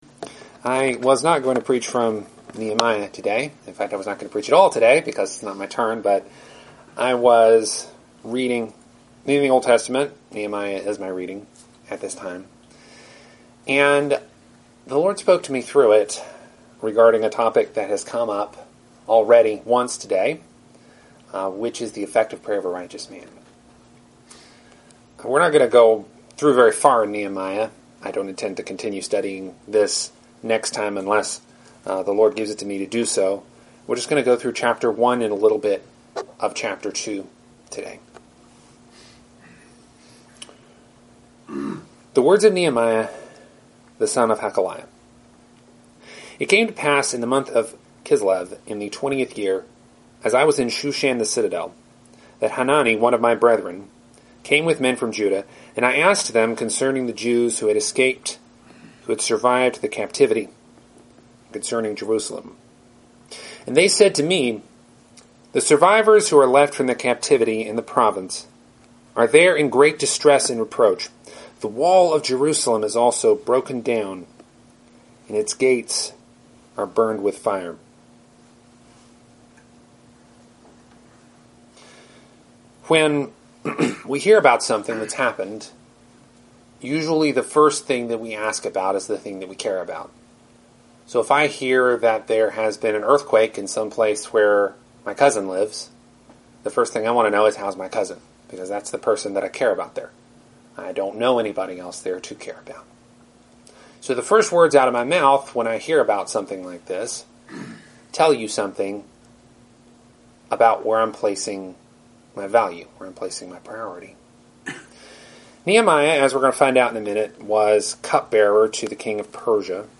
Teaching For April 3, 2017